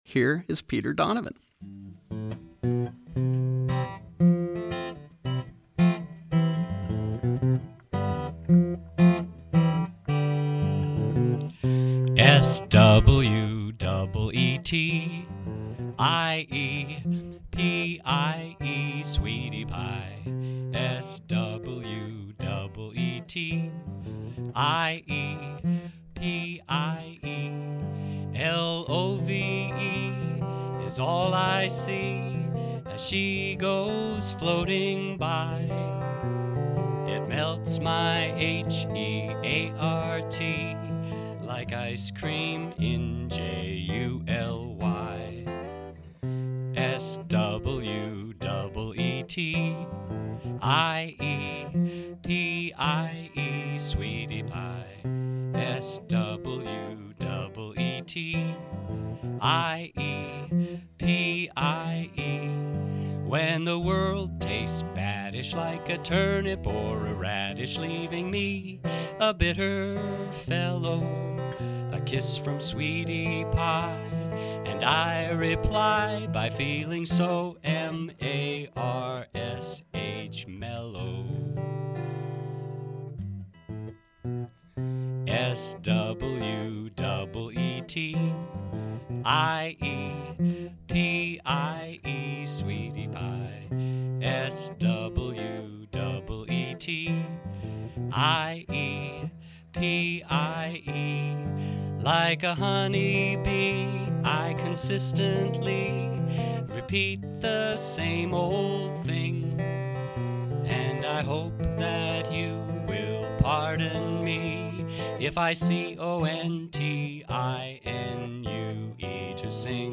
Live Music: Pittsburgh Songwriters Circle celebrating 10 years